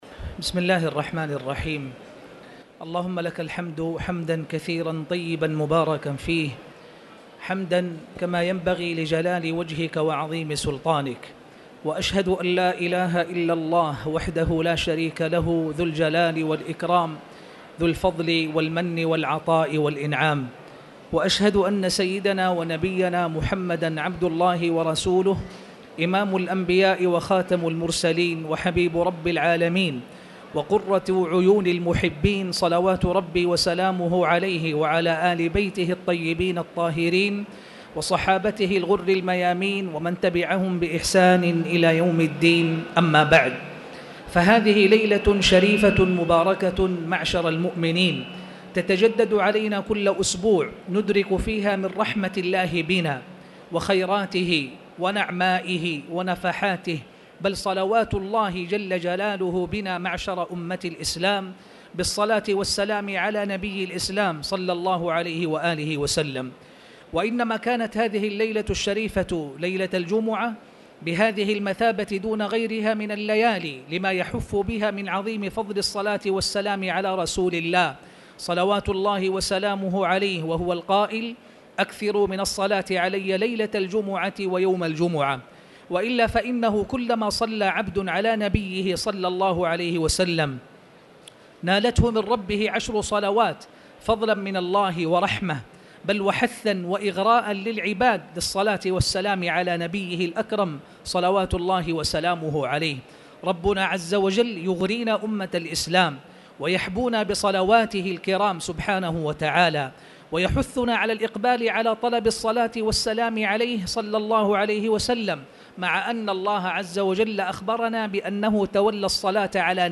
تاريخ النشر ١٩ شعبان ١٤٣٧ هـ المكان: المسجد الحرام الشيخ